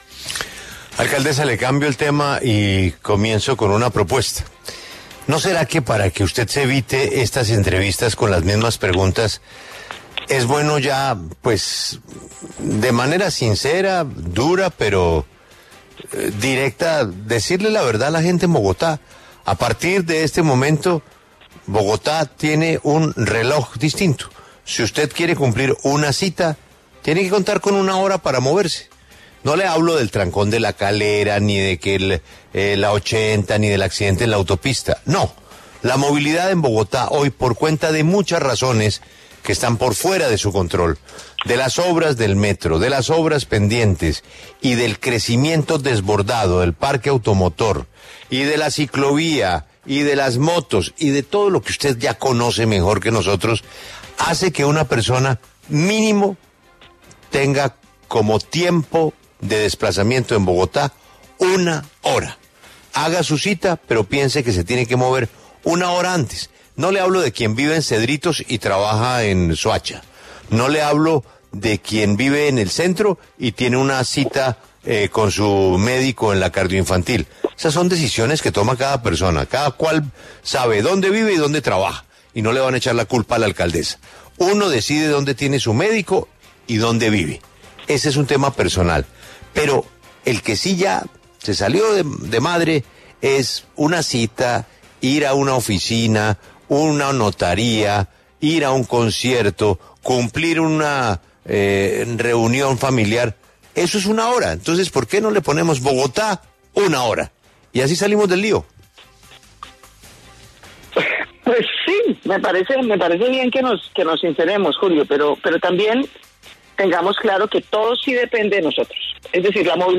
En entrevista con La W, la alcaldesa de Bogotá, Claudia López aseguró que los problemas de movilidad que enfrenta Bogotá “son por la manera en la que decidimos cómo movernos”.